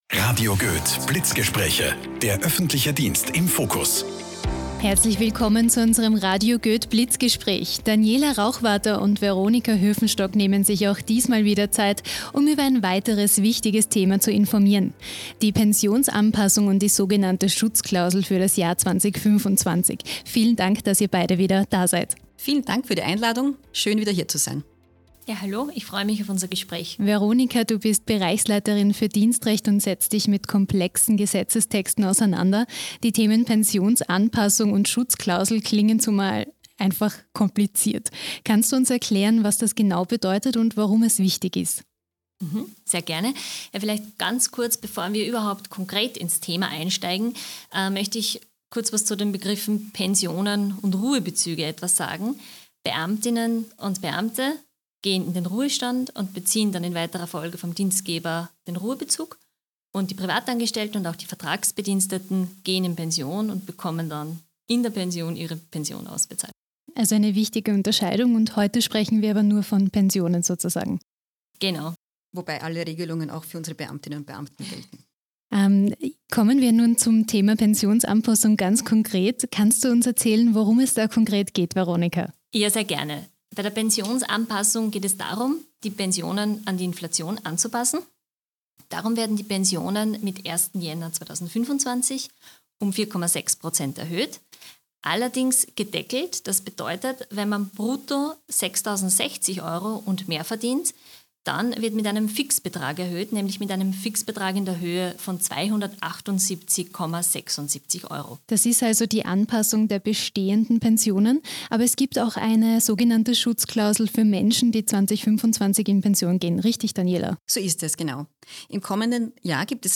Blitzgespräch